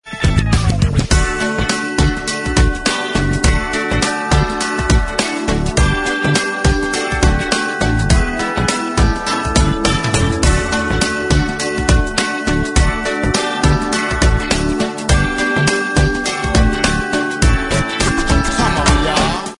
Kategorien: Weihnachten